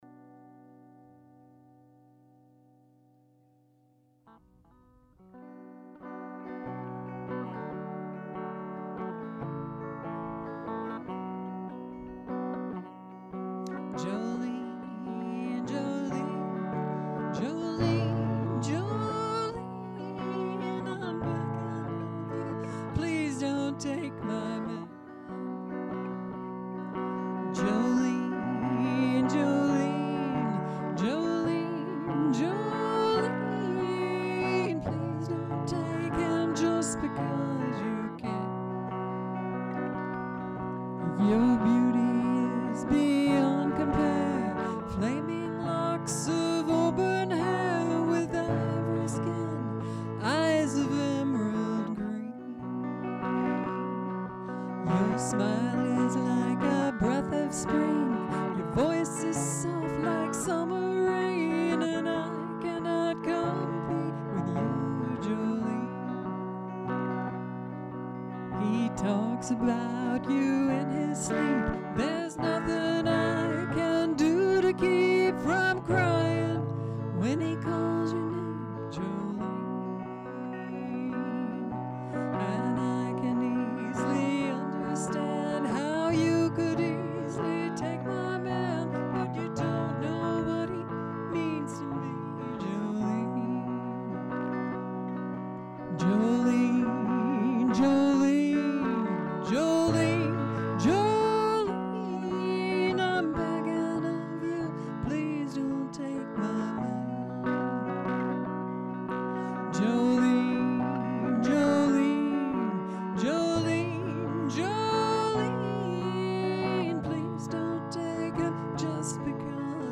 Remastered version of 10/17 early take.